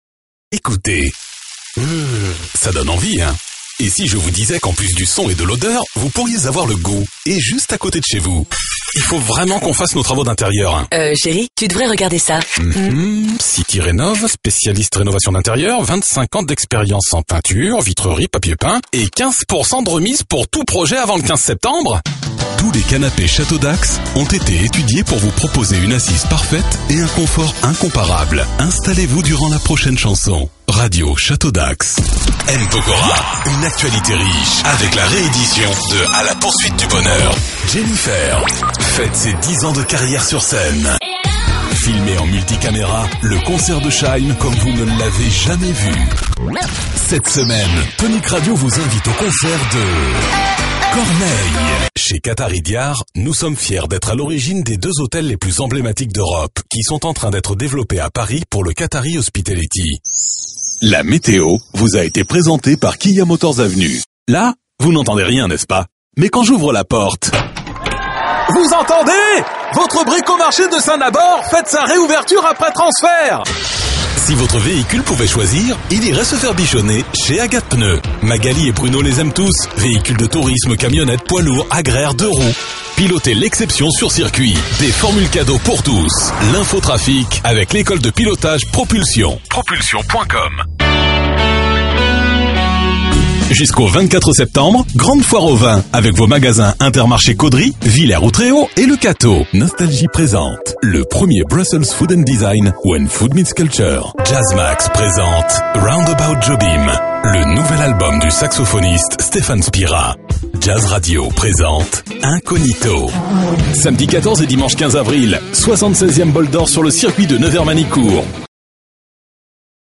Voix homme medium grave pour Pub Jingle Institutionnel
Sprechprobe: Werbung (Muttersprache):